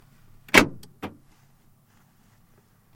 汽车电动门锁
描述：车门电动锁（四门）。
Tag: 汽车门 电源 电源锁